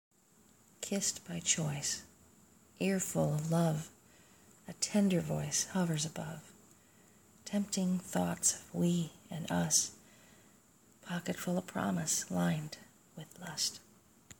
The following micropoetry is based on the Wed 3/12/15 fieryverse poetry prompt challenge as part of Spoken Word Wednesday.